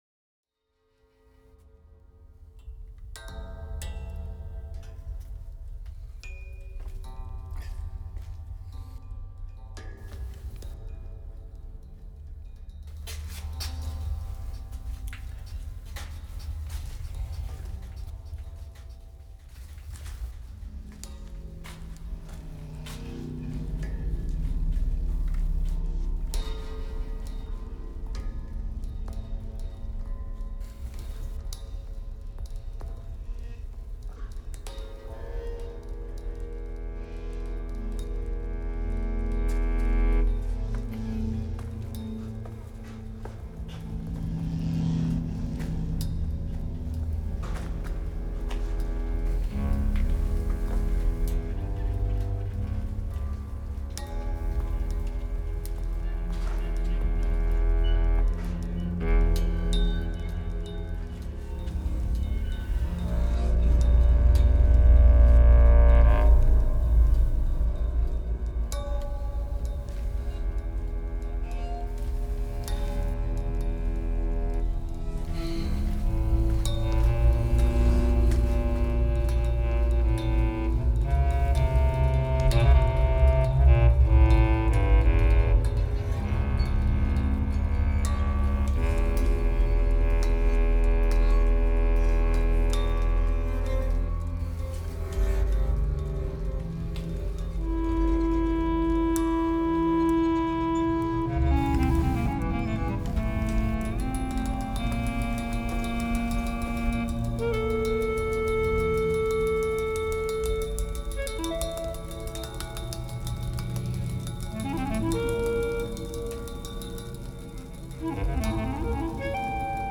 Pour instruments & Sons enregistrés au théâtre gréco-romain de Catane.